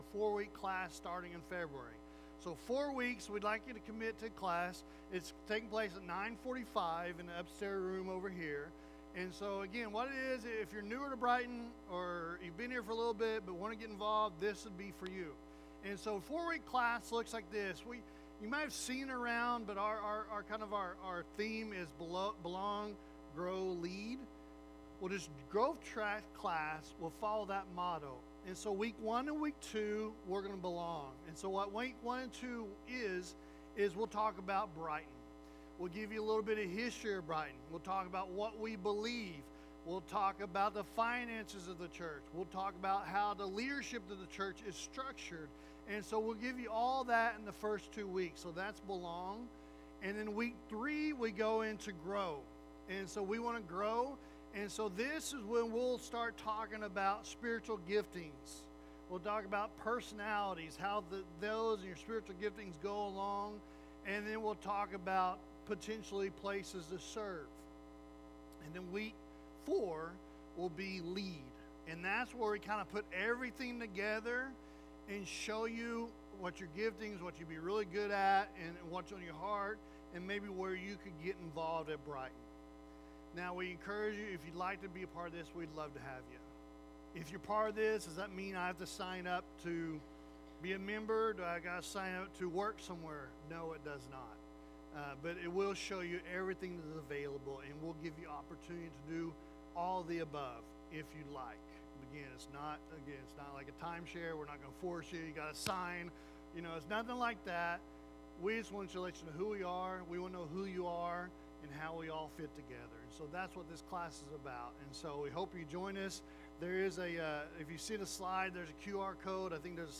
Sermons | Brighton Assembly